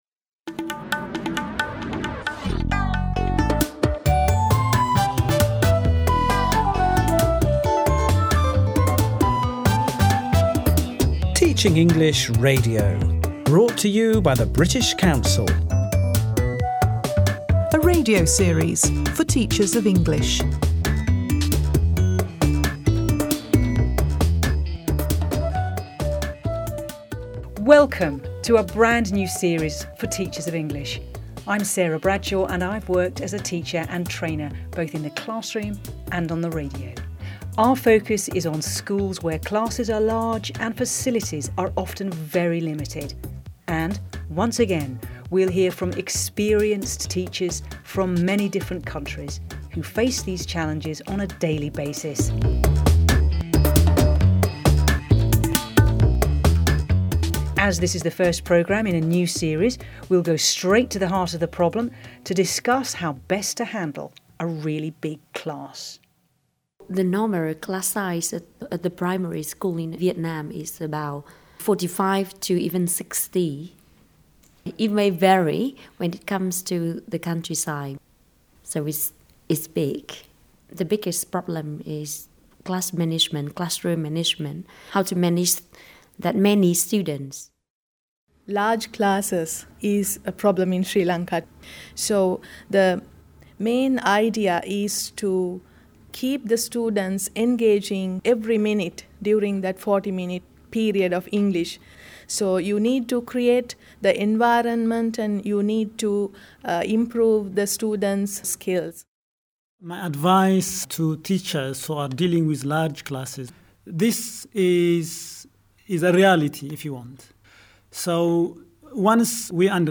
In this programme, we hear suggestions and tips from a number of different teachers who work with large classes.